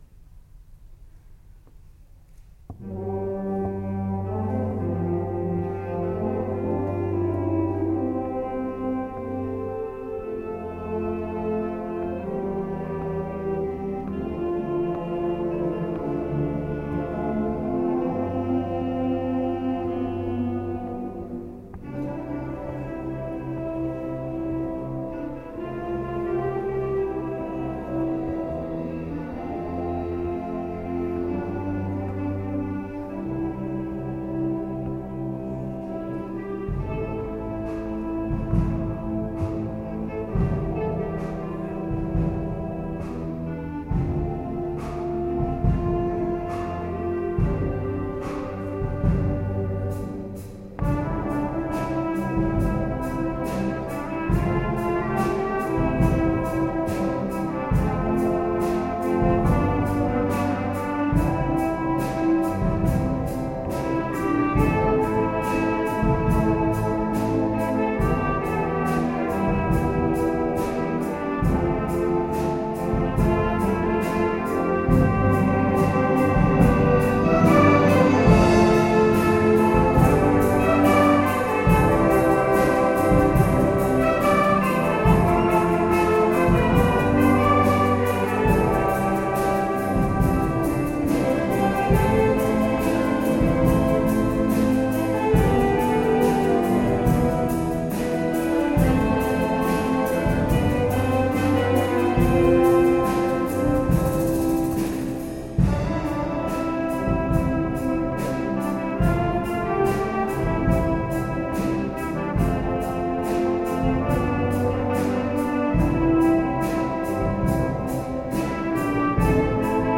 BANDA MUSICALE
Concerto di Natale 2011